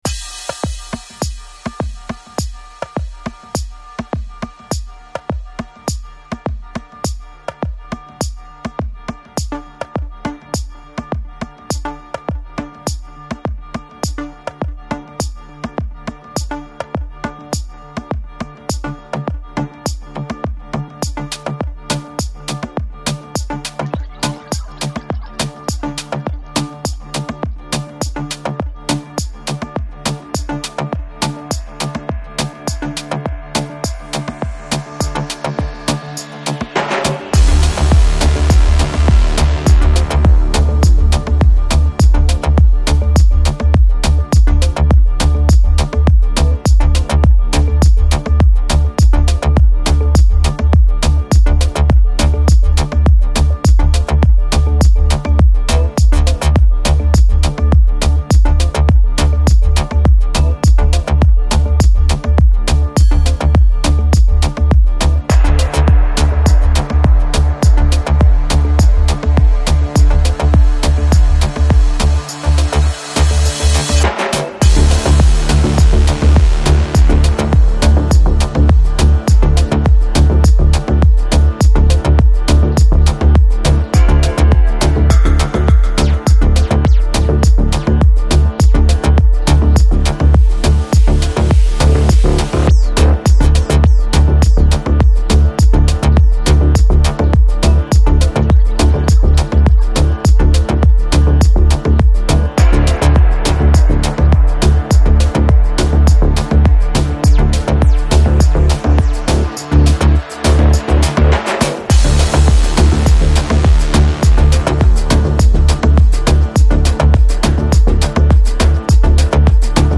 Жанр: Chill Out, Downtempo, Organic House, Ethnic, World